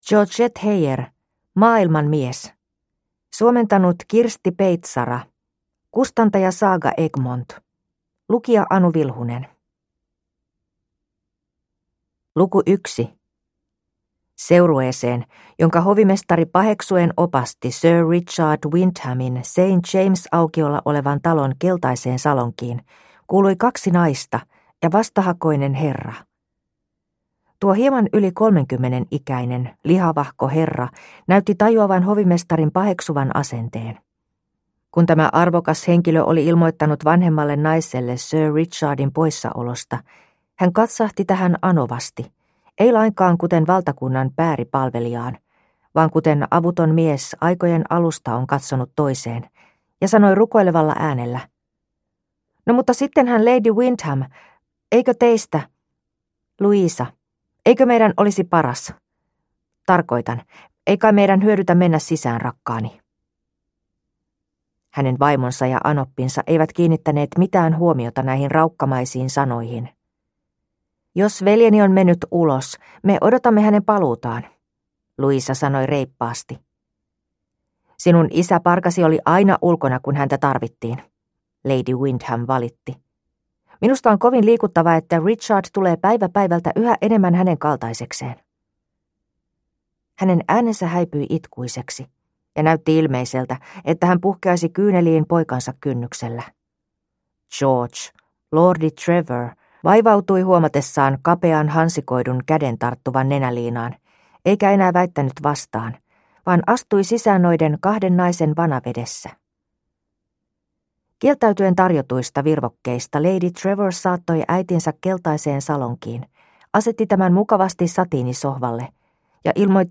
Maailmanmies – Ljudbok